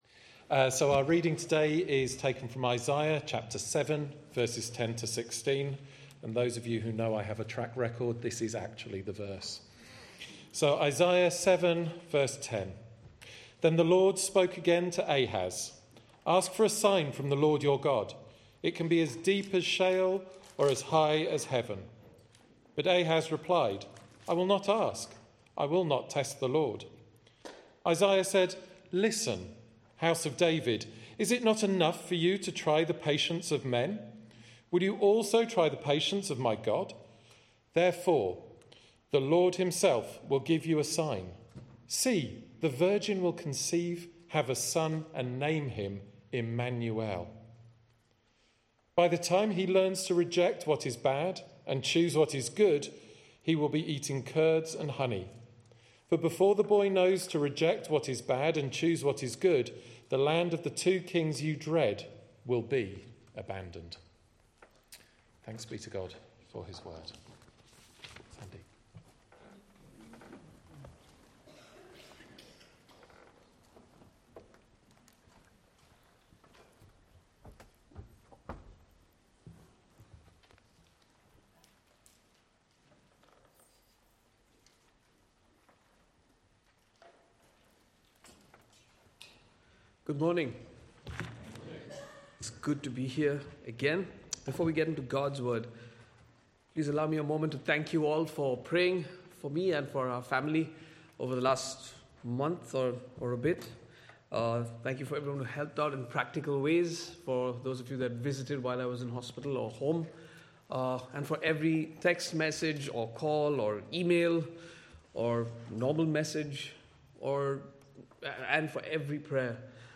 From Series: "Other Sermons"